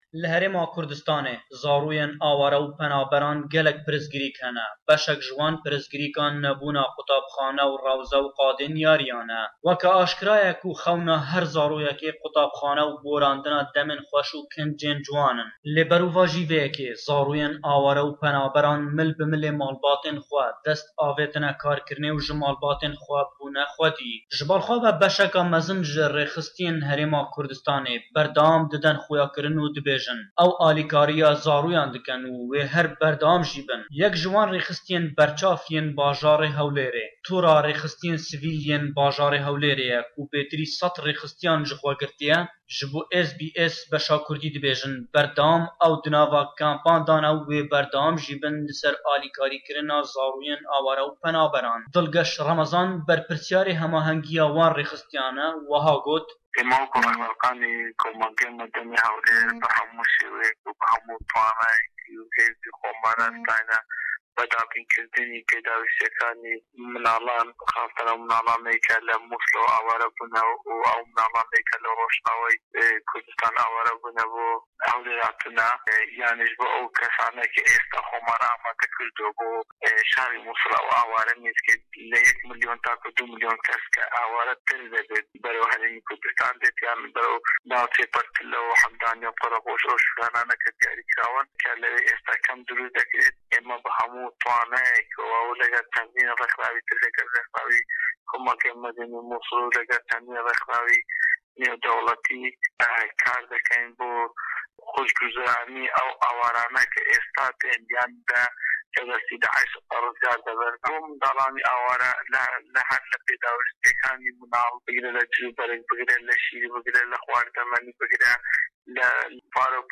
Gelo xizmetên ji bo wan, bi taybetî ji bo zarokên penaberan, hene têrê dikin. Bêtir ji vê raporta ji Hewlêr.